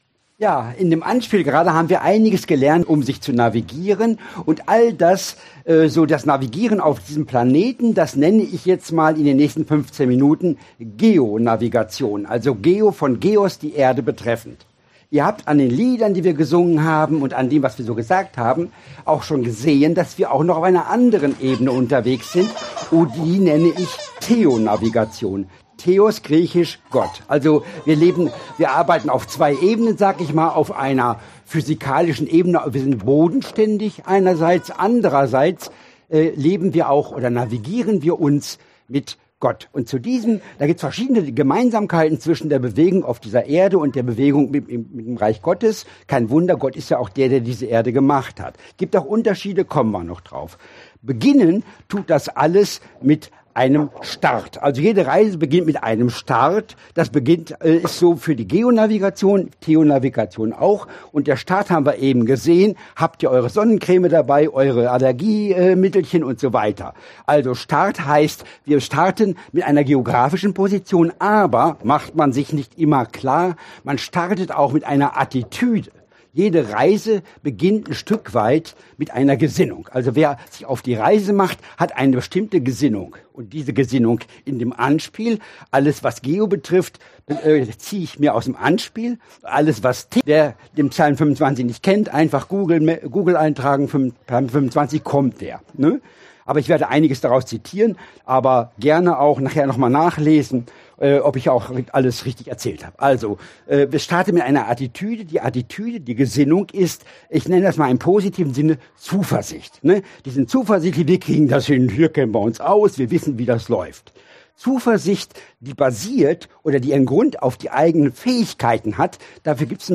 4-5 Dienstart: Predigt « Jüngerschaft